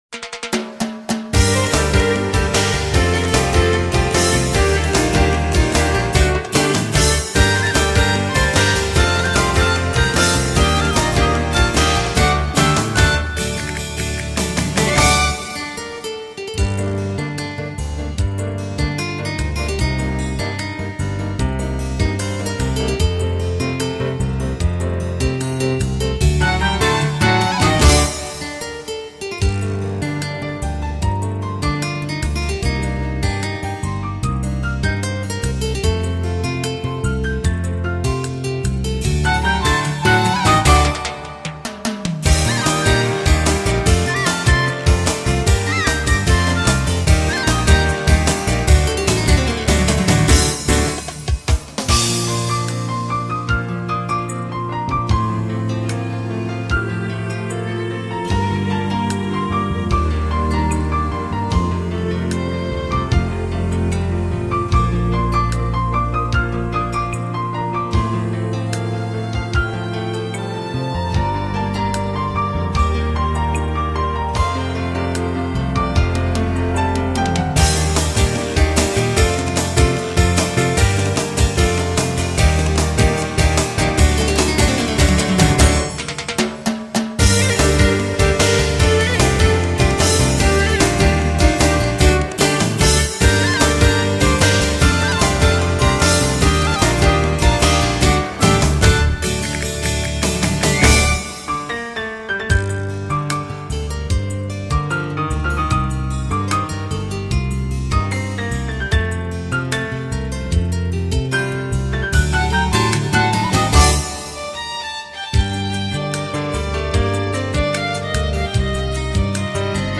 钢琴曲
音乐风格：New Age
巧妙地把耳熟能详的欧洲经典音乐旋律和奔放的拉丁节奏结合在一起。